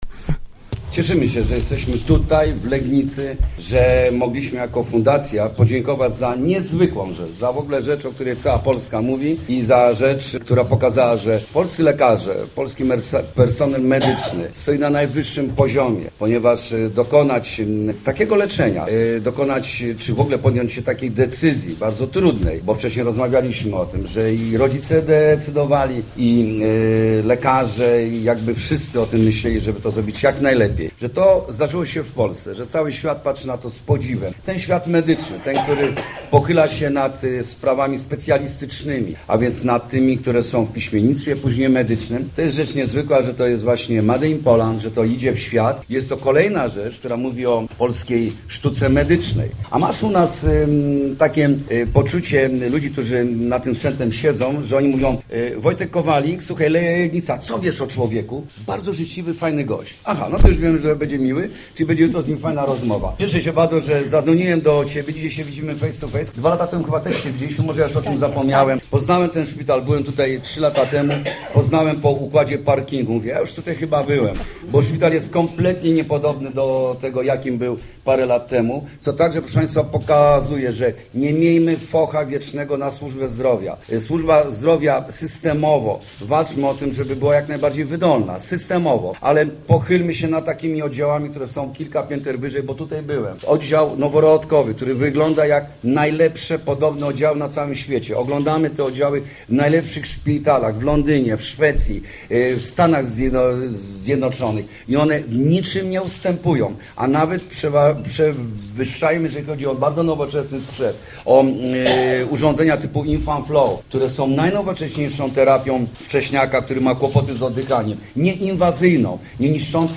Cały świat patrzy na to z podziwem, to jest rzecz niezwykła, że to jest Made in Poland - mówił na spejclanej konferencji prasowej Jurek Owsiak.